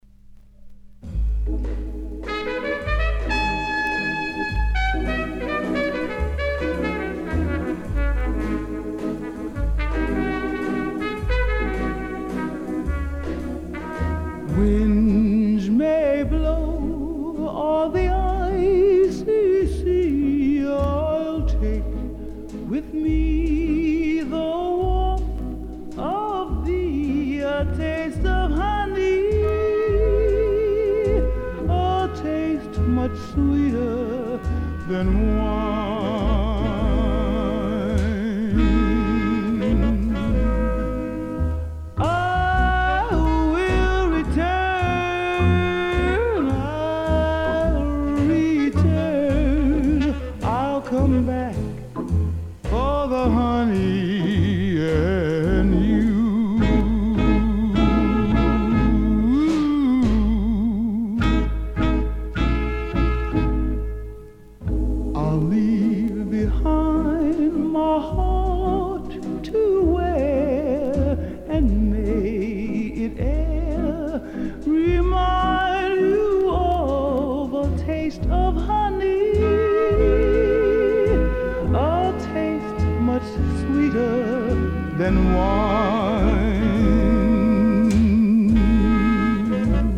★ 毫不費力的音準掌控，拿捏得恰到好處的傲人嗓音。